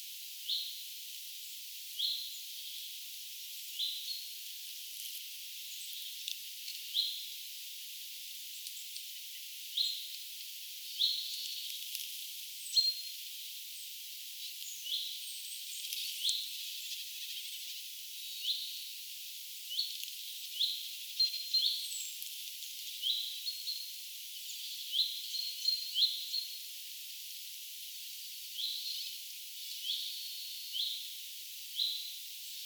peipon hyit-huomioääntelyä